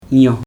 [ɲõ] noun bee
Dialect: Hill Remo